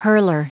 Prononciation du mot hurler en anglais (fichier audio)
Prononciation du mot : hurler